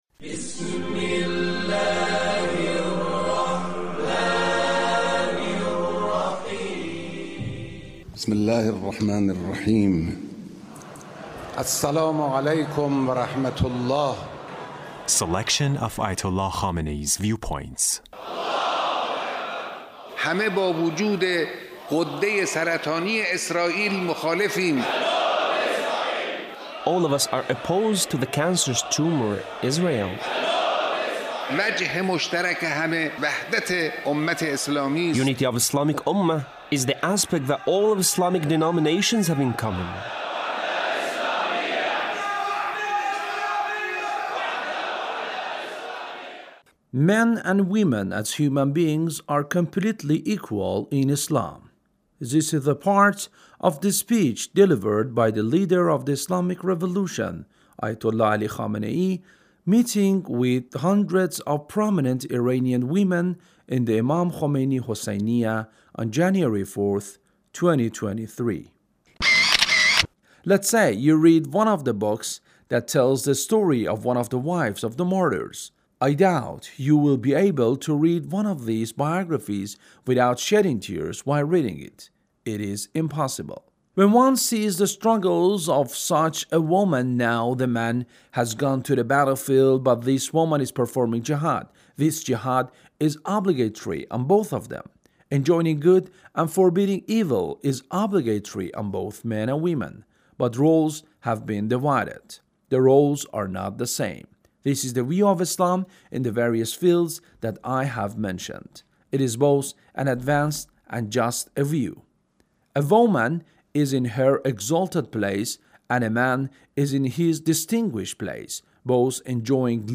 Leader's Speech meeting with hundreds of prominent Iranian women